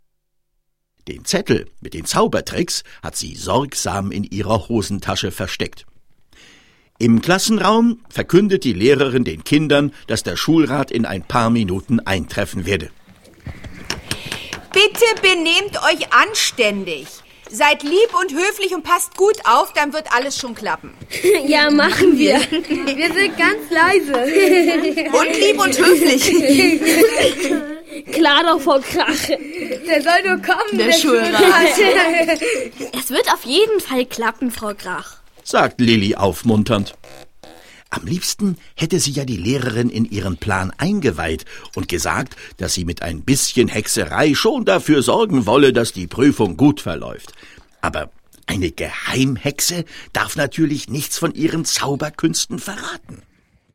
Ravensburger Hexe Lilli - Folge 4: stellt die Schule auf den Kopf ✔ tiptoi® Hörbuch ab 4 Jahren ✔ Jetzt online herunterladen!